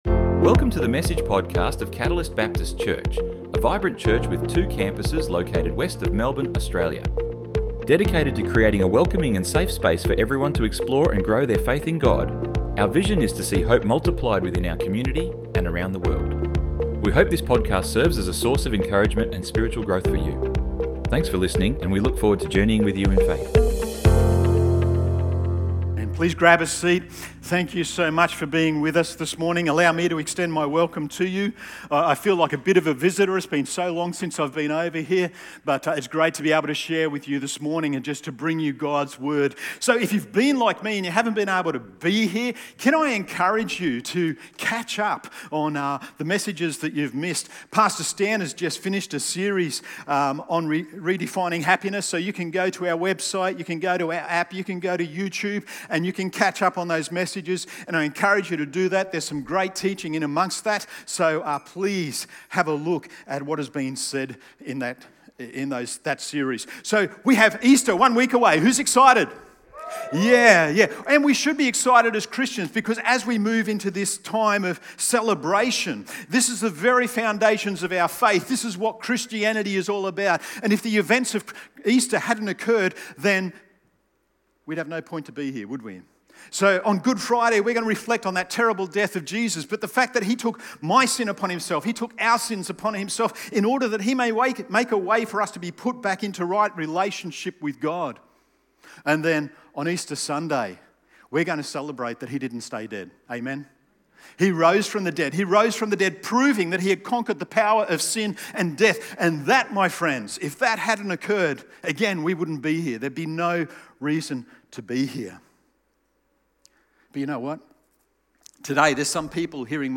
In this powerful and honest message from Matthew 11:1-15, we explore the story of John the Baptist—once so sure of who Jesus was, now sitting in a dark prison cell wondering if he got it all wrong. Whether you’re battling sickness, disappointment, unanswered prayers, or deep personal loss, this sermon reminds us that doubt doesn’t disqualify faith—it’s part of the journey.